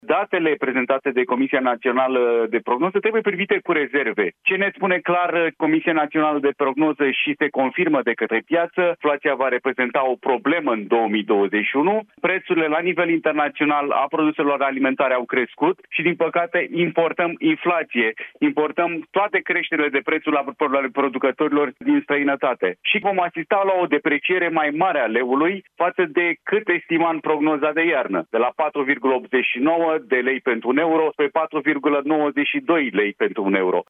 Analistul economic